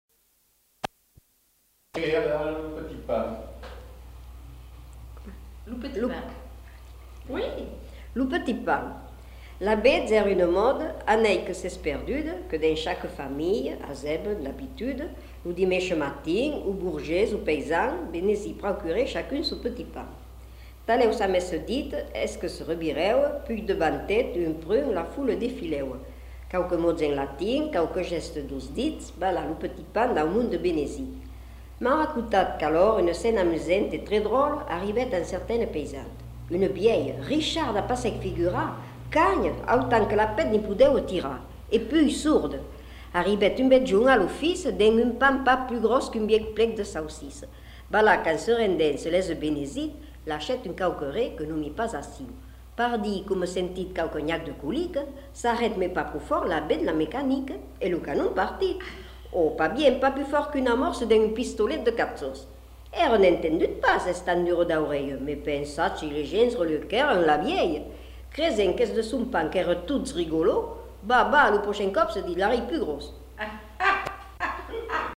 Formulettes enfantines